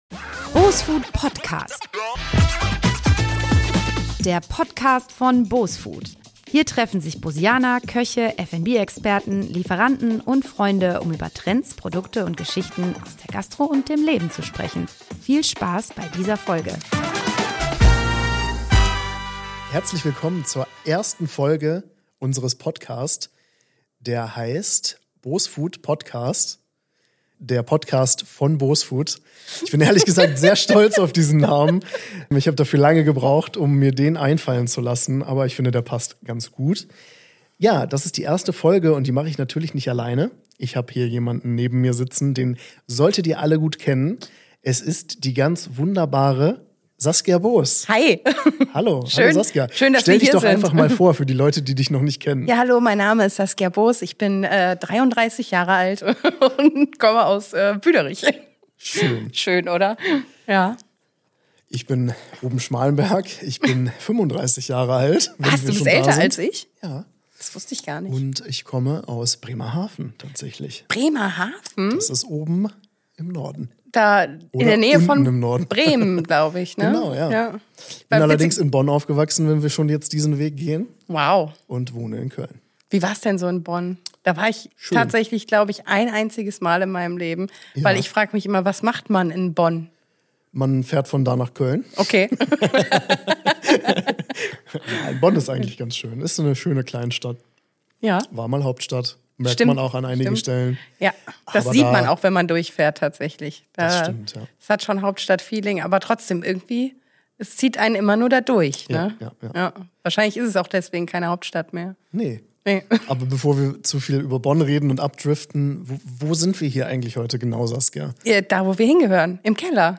In unserer ersten Folge wird es persönlich, humorvoll und ein bisschen nostalgisch.